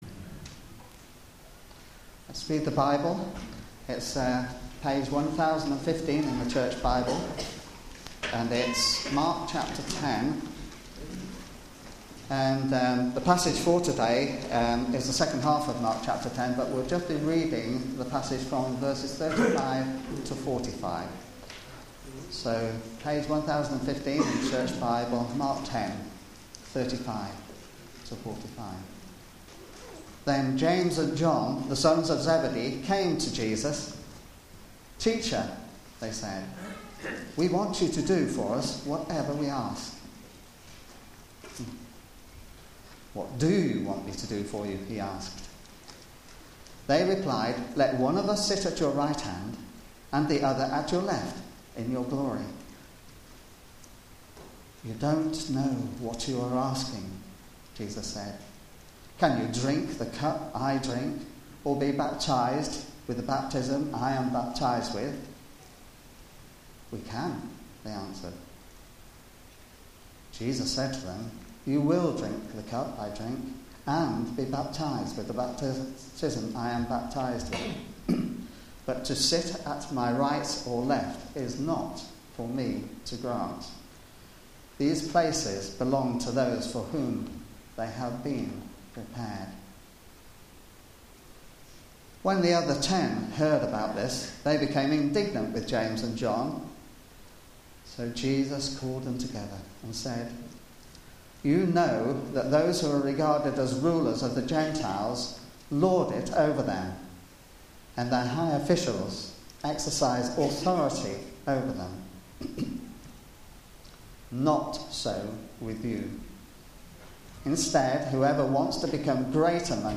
Media for Sunday Service
Series: The Gospel of Mark Theme: Recognising Jesus Sermon